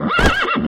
马跳.wav